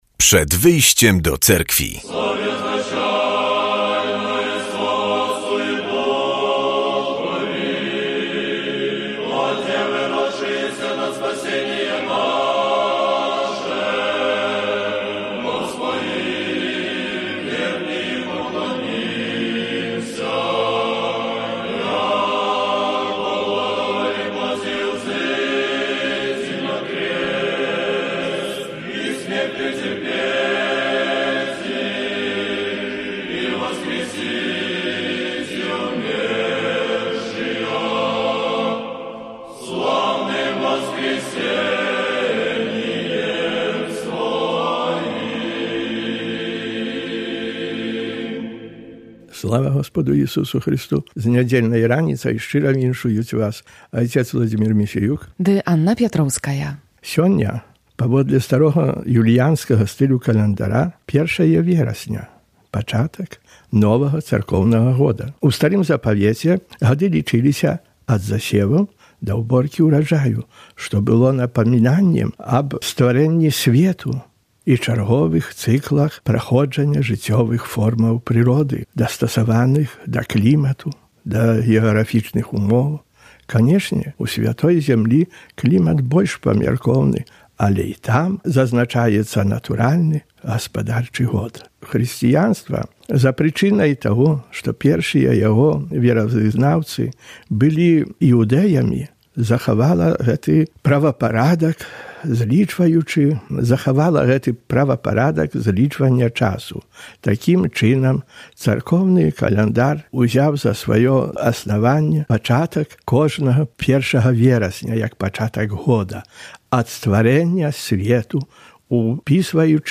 W audycji usłyszymy kazanie na temat niedzielnej Ewangelii i informacje z życia Cerkwi prawosławnej. Przybliżymy także sylwetki nowych świętych - męczenników katyńskich, których kanonizacja odbędzie się 16 września w cerkwi Hagia Sophia w Warszawie.